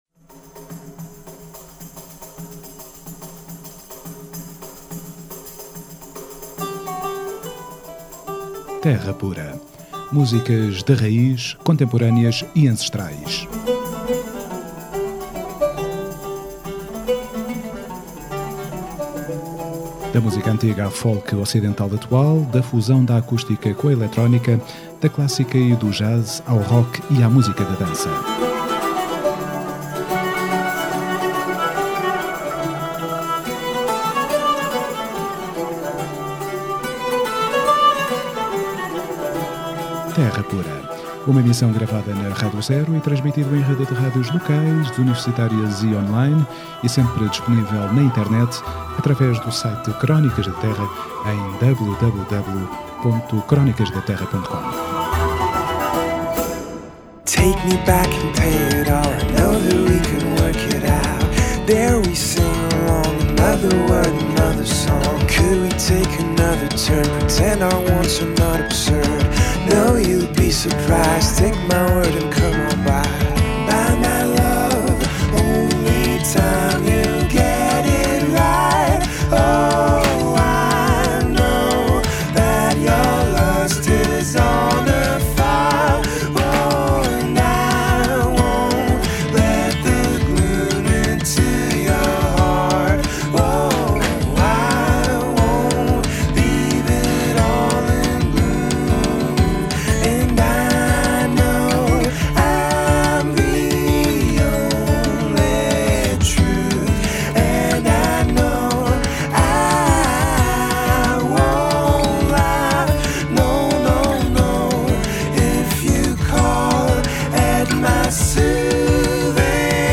Um disco de 11 canções? ou uma peça-concerto de 42 minutos em 11 actos? Questões que se impõem quando se escuta o “post-rock” melancólico, luminoso e pastoral desta “orquestra” de seis elementos.